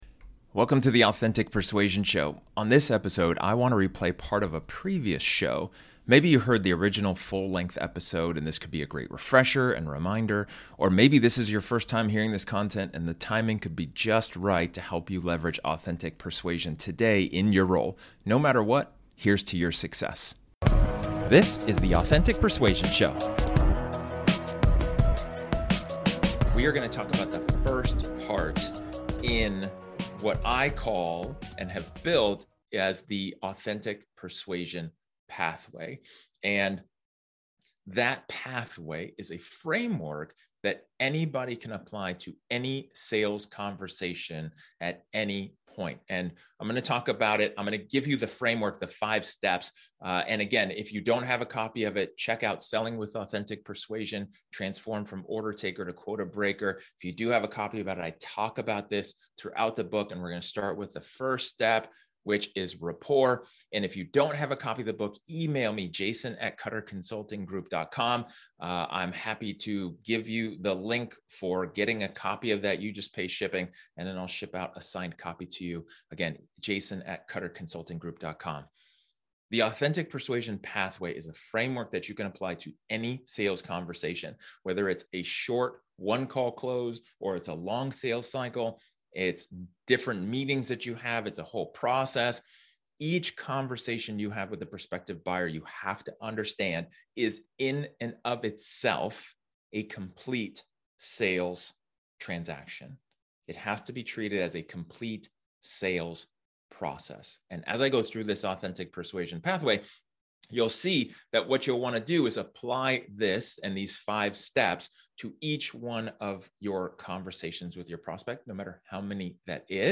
This episode is an excerpt from one of my training sessions where I talk about building rapport.